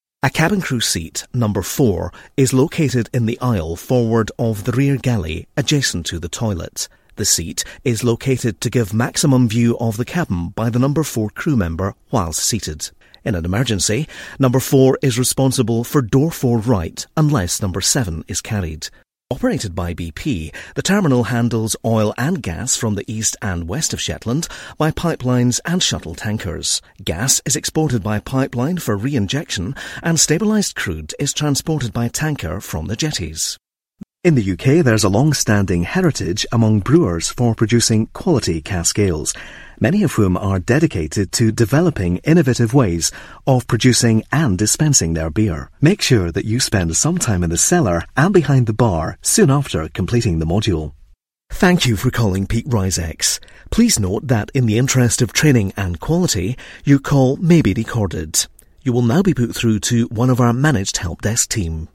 Mellow friendly characterful.
schottisch
Sprechprobe: Industrie (Muttersprache):
Authentic, Conversational, Convincing, Compelling, Natural,Positive,Punchy.